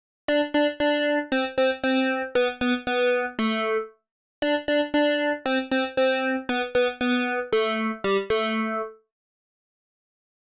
Подскажите, пожалуйста, как избавиться от этой Cher (или T-Pain)? 2.